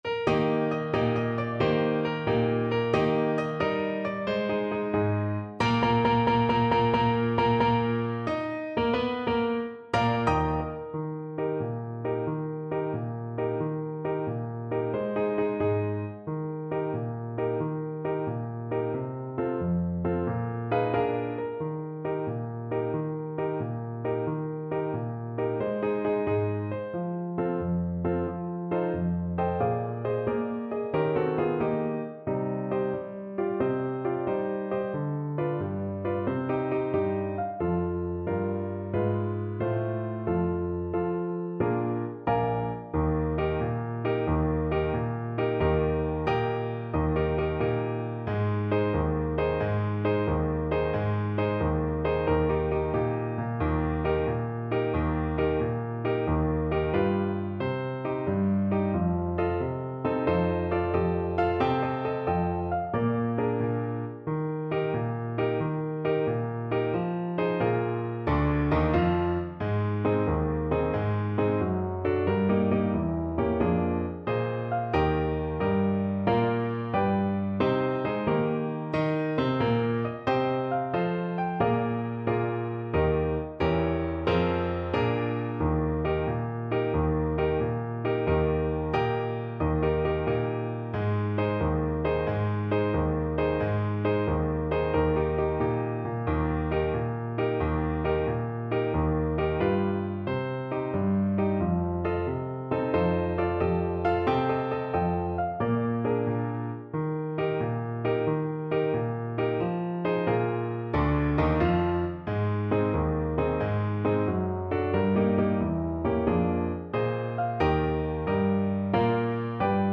Clarinet version
6/8 (View more 6/8 Music)
Allegro .=90 (View more music marked Allegro)